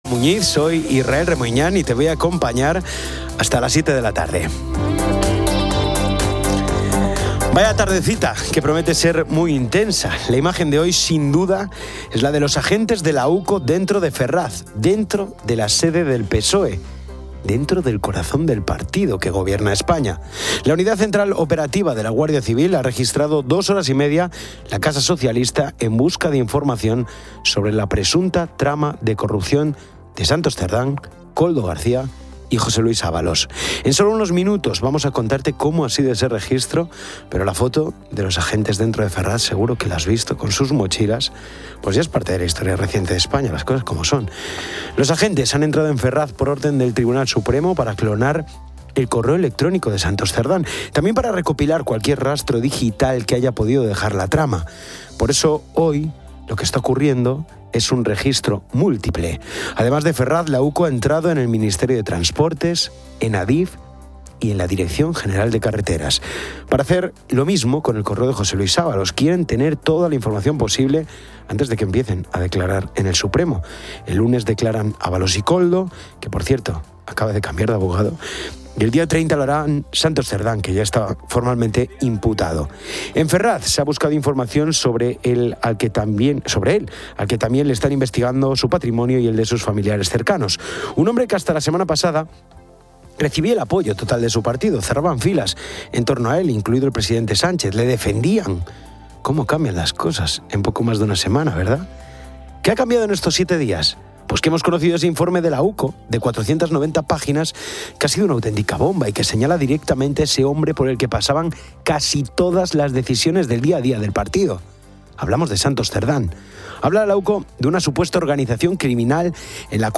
También entrevista al alcalde de Marchamalo, donde han retirado la placa conmemorativa de Santos Cerdán.